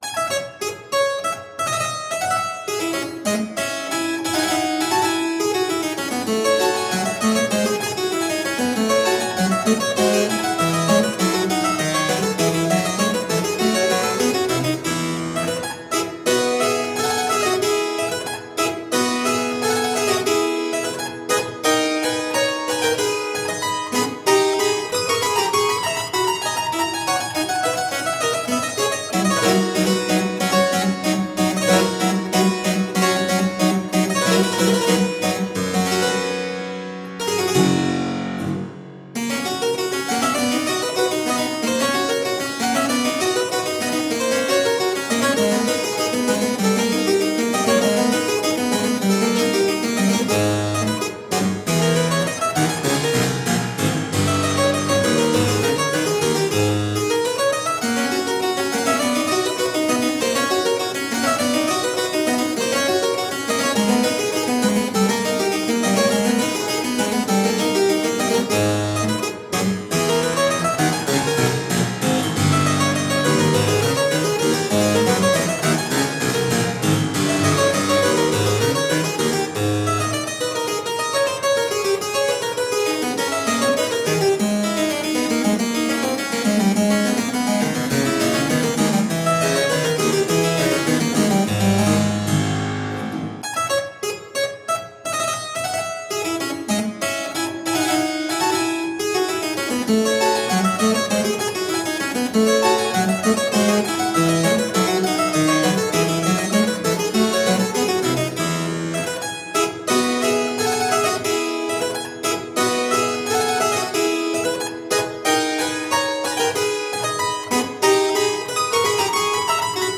Delicious harpsichord rarities.
Classical, Classical Period, Instrumental, Harpsichord